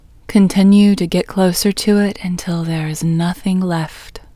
IN Technique First Way – Female English 26